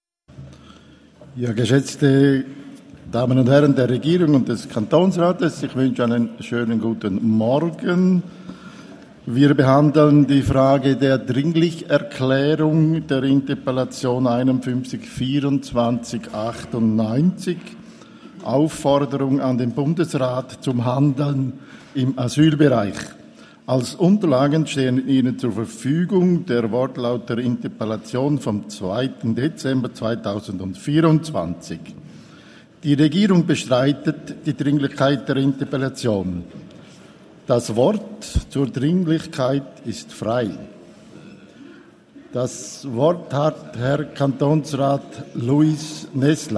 Session des Kantonsrates vom 2. bis 4. Dezember 2024, Wintersession
3.12.2024Wortmeldung